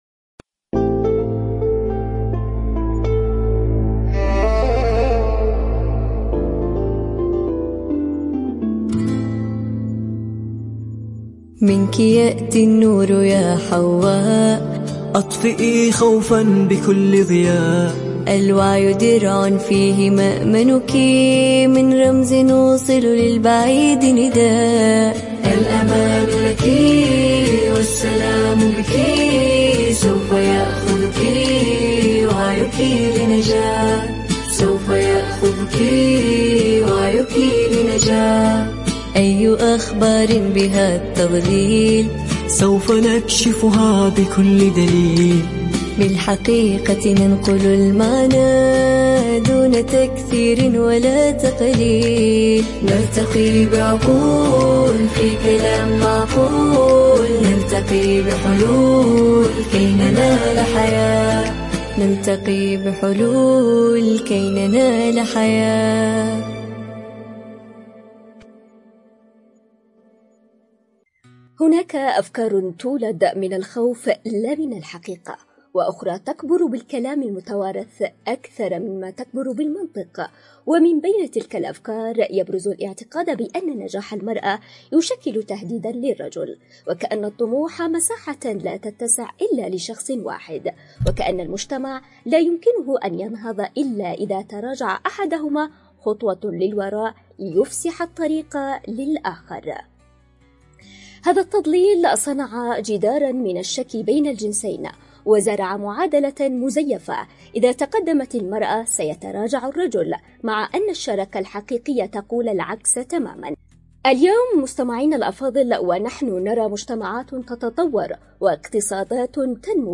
عبر أثير إذاعة رمز